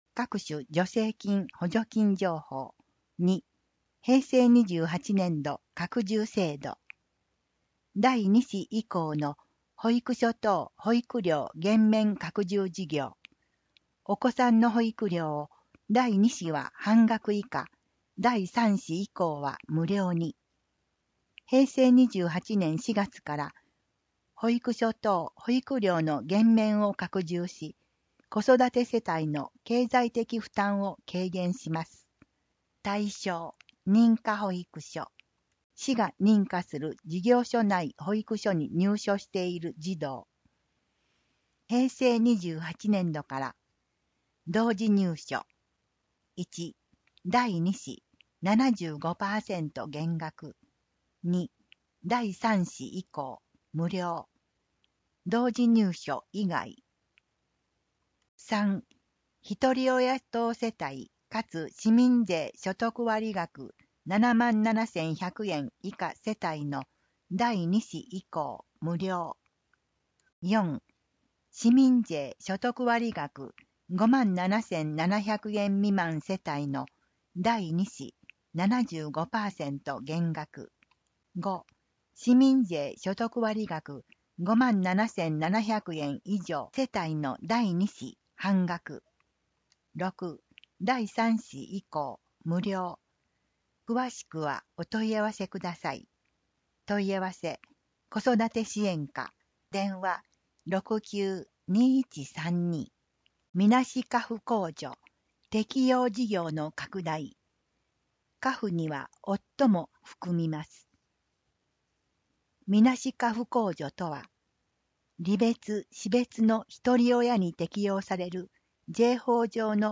声の広報かさおか　平成２８年４月号